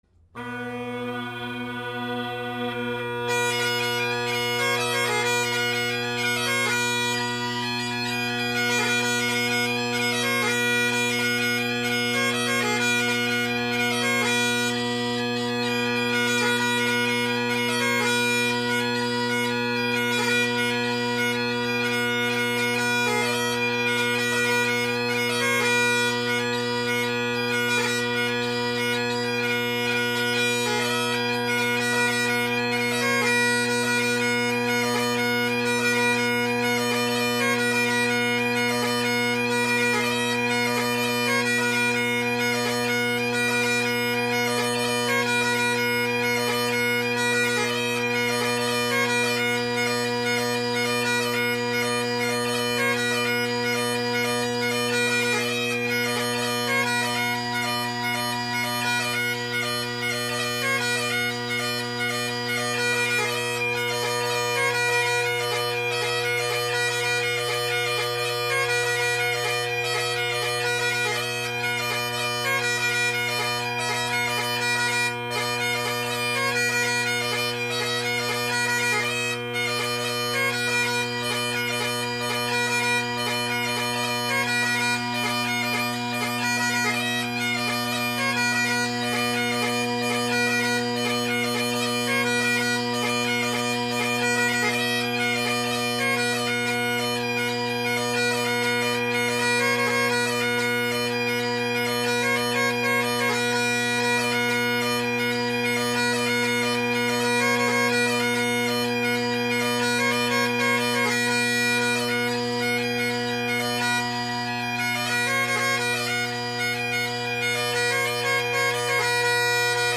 Drone Sounds of the GHB, Great Highland Bagpipe Solo
So the audio that follows is of my band set – the drones + these new drones.
Sorry about the sharp F and flat high A, at times, and occasional cut out.
The mic was placed behind me since we’re listening to the drones here (I’m a drone guy, what can I say?).
The bass drone may be a tad quiet, of course Redwood tenor reeds offer a bold tone without the harshness of other bold tenor reeds, so it may be relative.